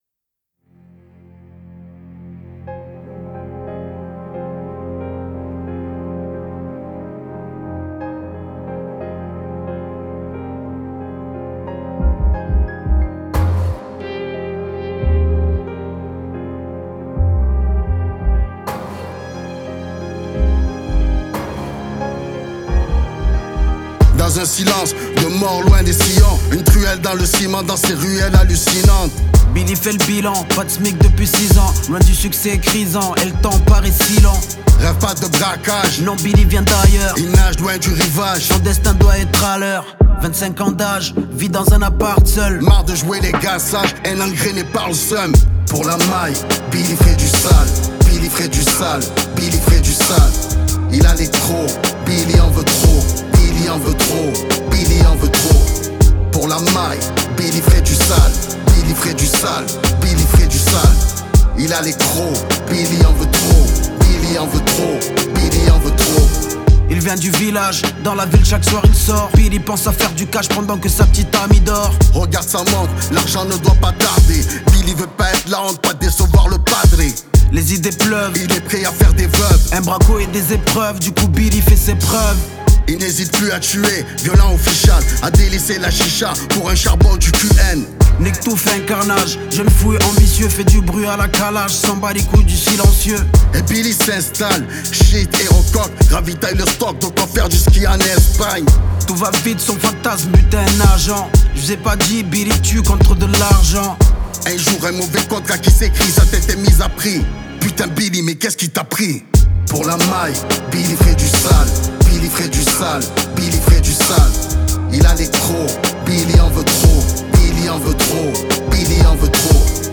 25/100 Genres : french rap Télécharger